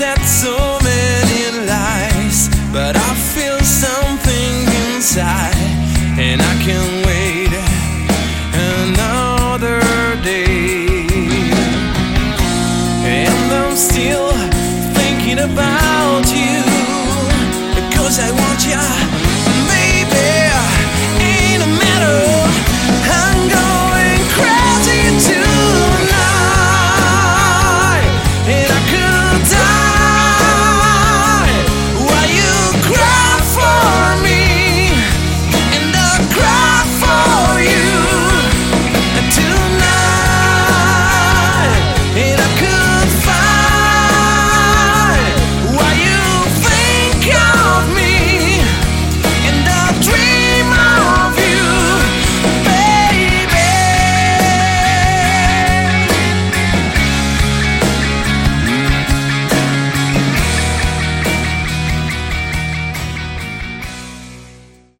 Category: Hard Rock
Vocals
Guitar
Bass
Drum